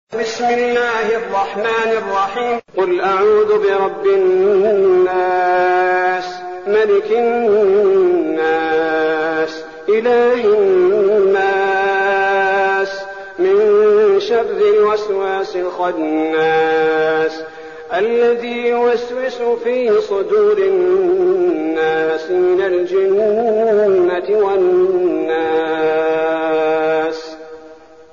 المكان: المسجد النبوي الشيخ: فضيلة الشيخ عبدالباري الثبيتي فضيلة الشيخ عبدالباري الثبيتي الناس